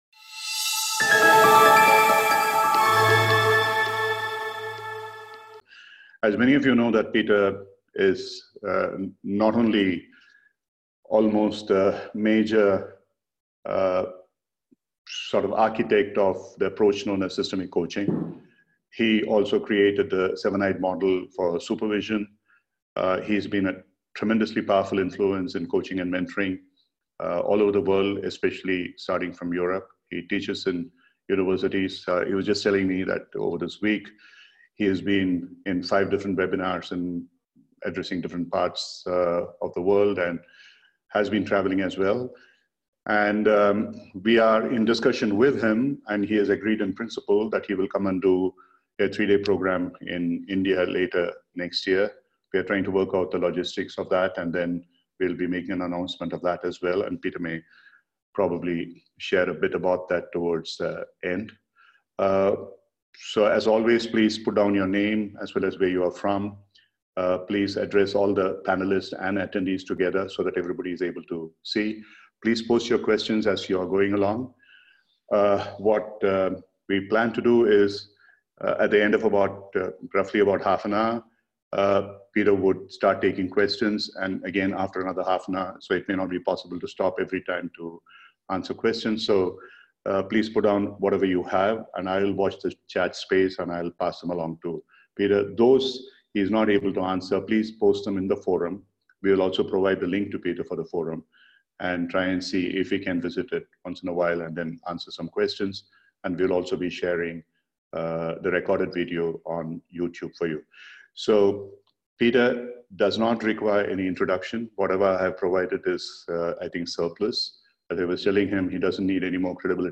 This session was recorded as part of the ICW 2024 Celebration